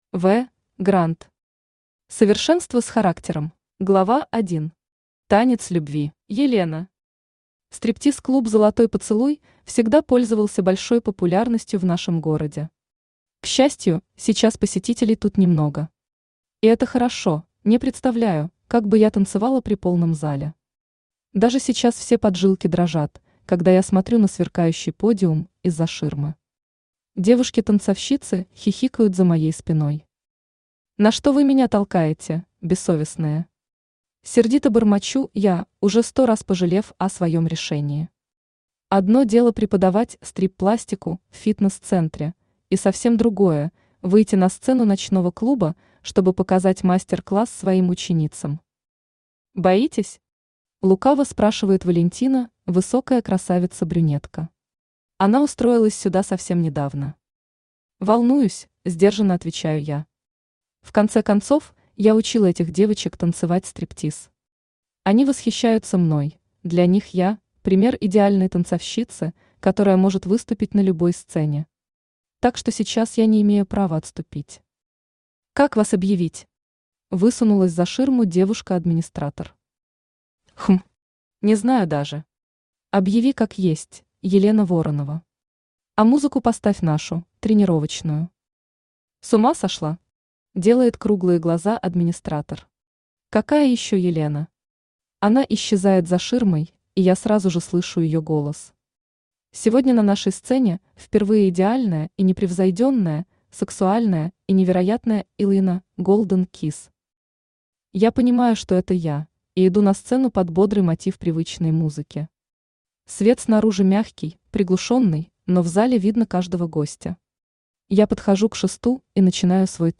Аудиокнига Совершенство с характером | Библиотека аудиокниг
Aудиокнига Совершенство с характером Автор В. Гранд Читает аудиокнигу Авточтец ЛитРес.